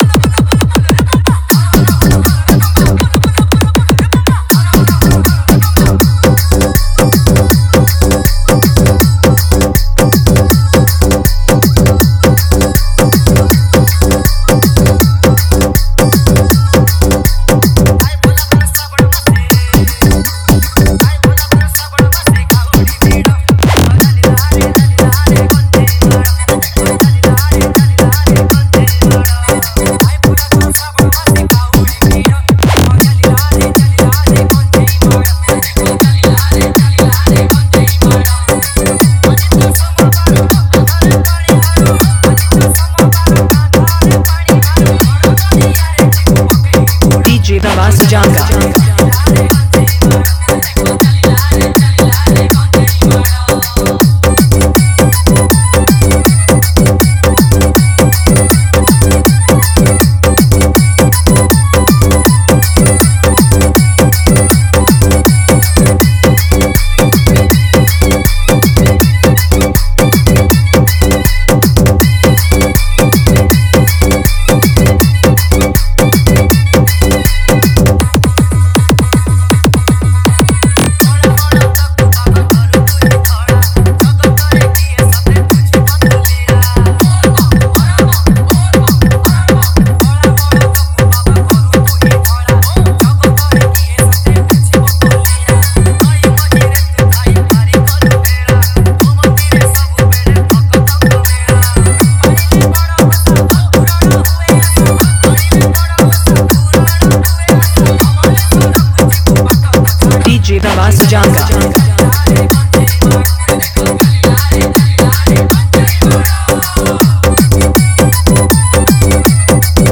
Bolbum Special Dj Song